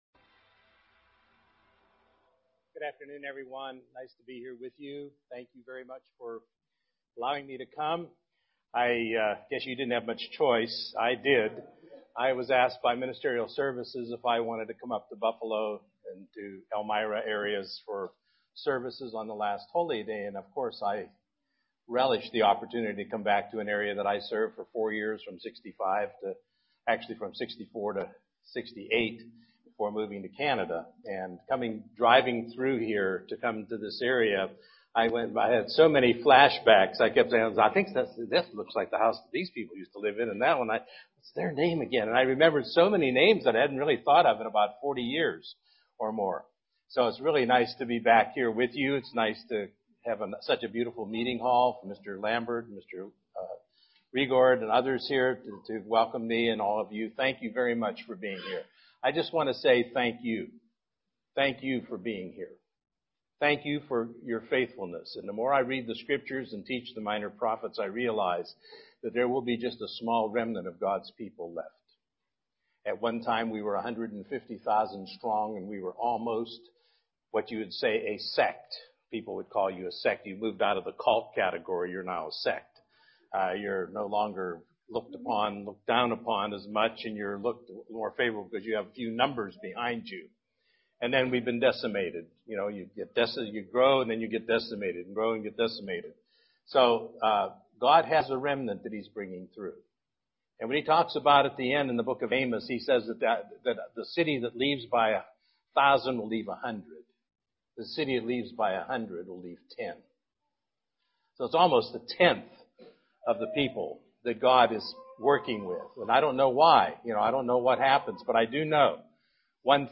Biblical examples of people who lost their focus. And how we can keep our focus on our Christian lives. This message was given during the Days of Unleavened Bread.